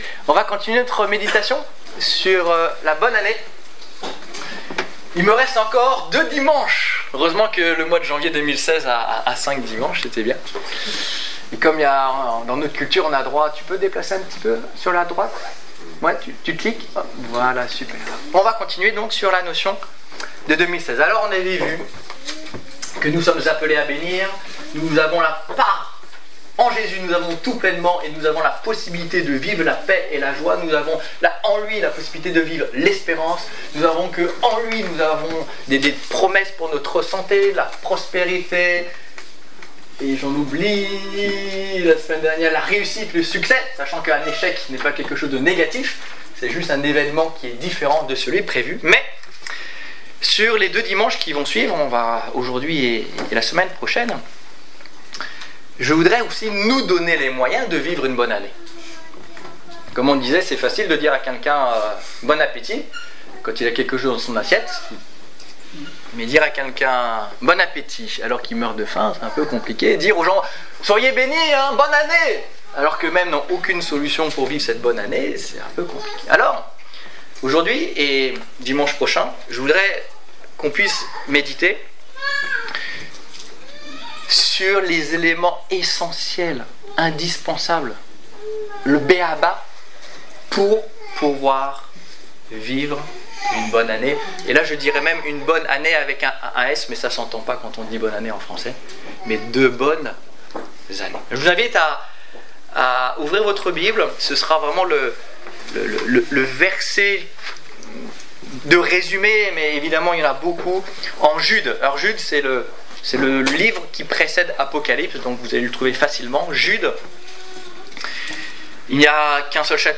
Exhortation - Culte du 24 janvier 2016